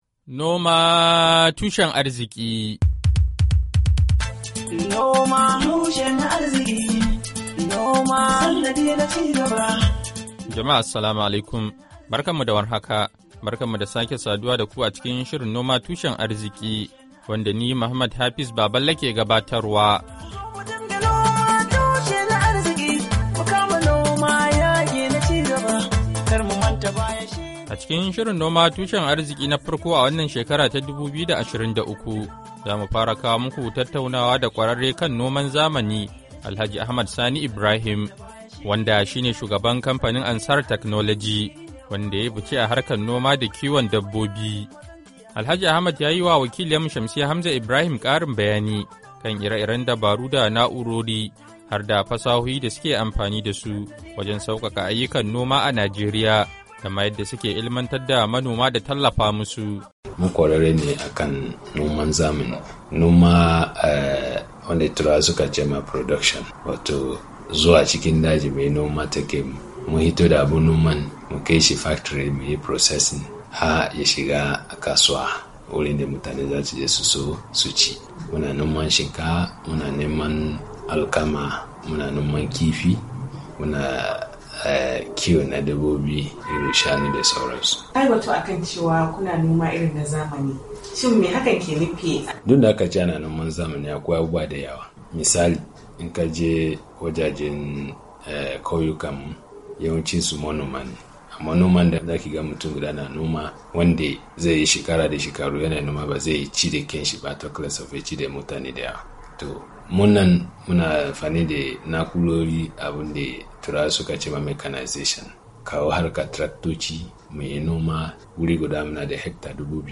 NOMA TUSHEN ARZIKI: Hira Da Kwararre Kan Noma Da Kiwon Dabbobi Na Zamani a Najeriya - Kashi Na Daya - Janairu 03, 2023